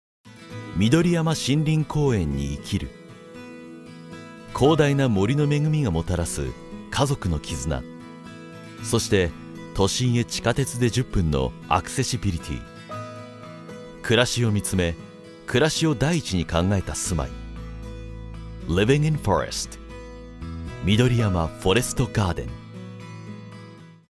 una voz de barítono enérgica y confiable
Muestras de voz nativa
Vídeos corporativos